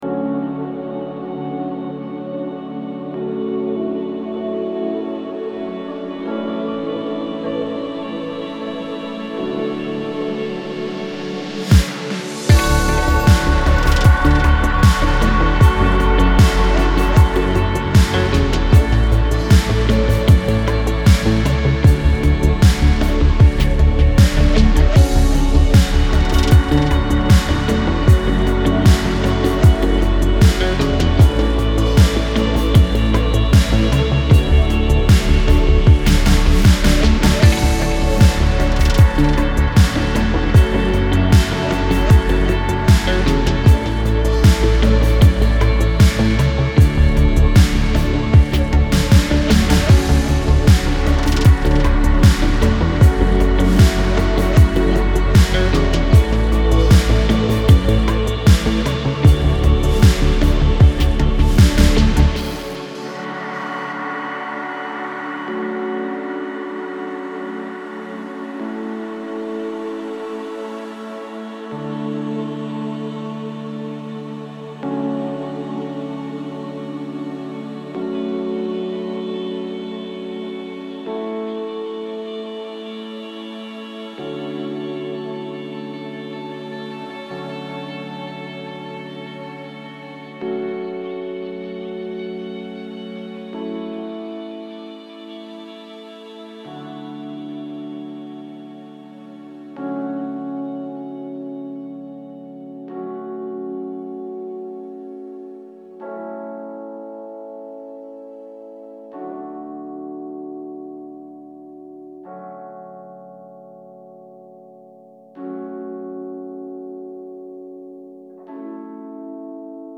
сердешно прошу дать критику на Ambient,Post Rock